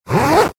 Index of /server/sound/clothing_system/fastener